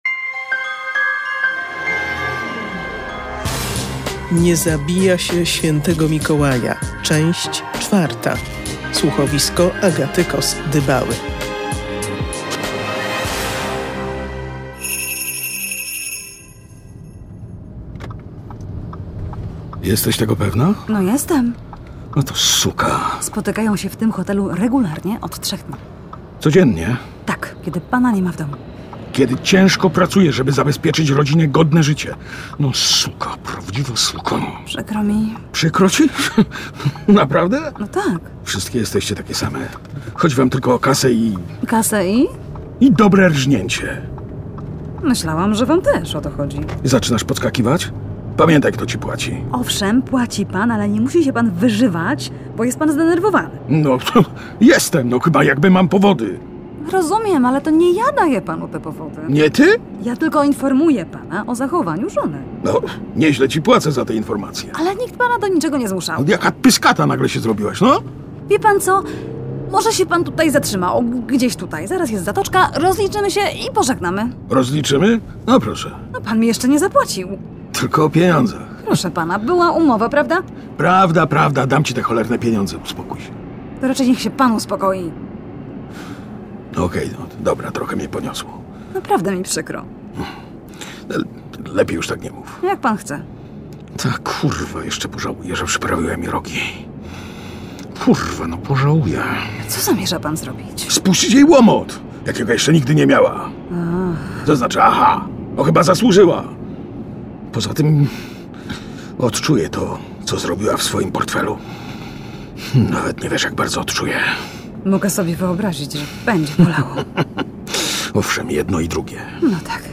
Bohaterowie słuchowiska o tym tytule zgłaszają zdanie odrębne.